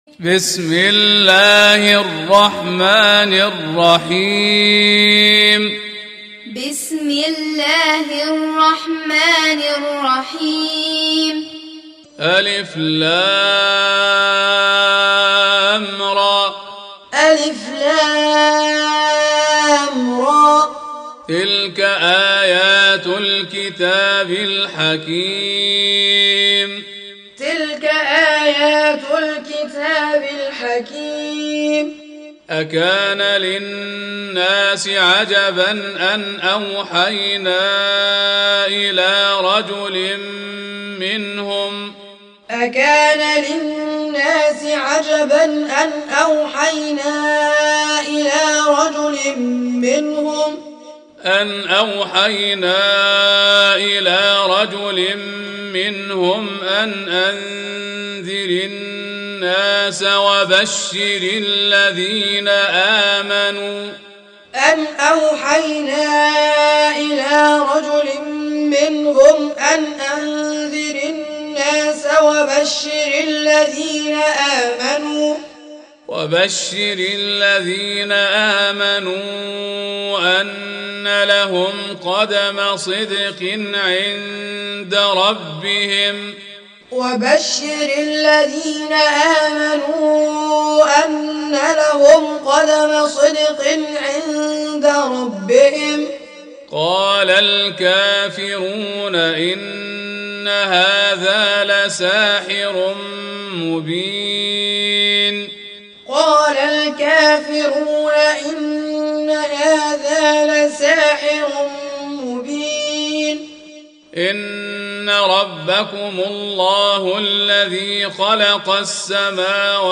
Surah Sequence تتابع السورة Download Surah حمّل السورة Reciting Muallamah Tutorial Audio for 10. Surah Y�nus سورة يونس N.B *Surah Includes Al-Basmalah Reciters Sequents تتابع التلاوات Reciters Repeats تكرار التلاوات